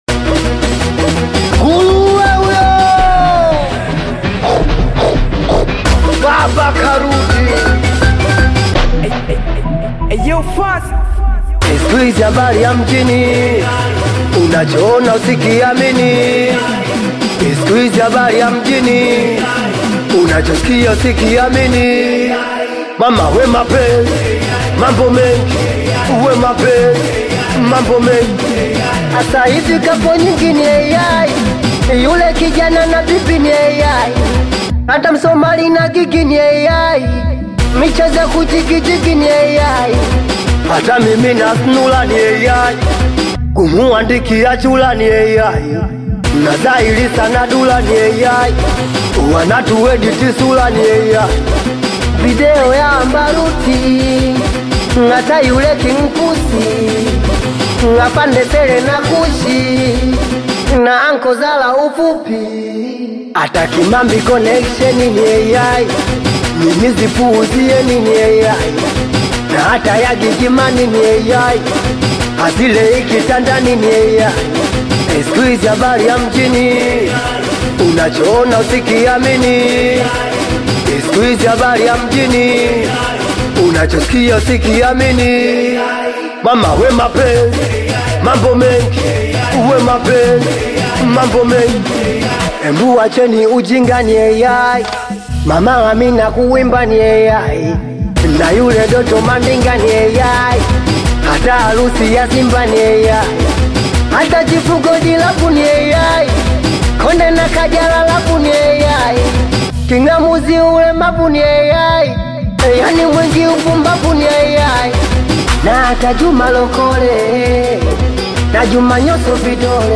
Known for his energetic delivery and authentic Singeli sound